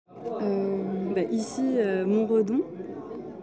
Chants, berceuses, paysages sonores... récoltés dans nos itinérances.
chant en "yaourt", d’origine ukrainienne